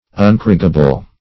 Search Result for " uncorrigible" : The Collaborative International Dictionary of English v.0.48: Uncorrigible \Un*cor"ri*gi*ble\, a. Incorrigible; not capable of correction.
uncorrigible.mp3